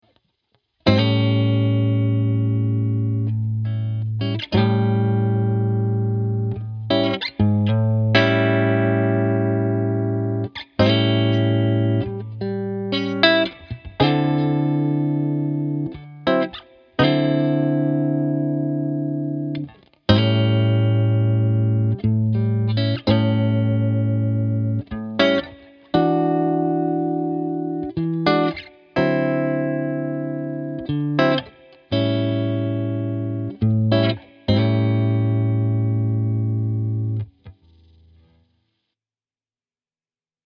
In the tab below you’ll see that we play a regular 12 bar blues, while replacing the D7 and E7 chord (as you would play in a regular 12 bar) with the D9 and E9 chords.
As you can hear this gives the overall feel of the 12 bar certain soulfulness.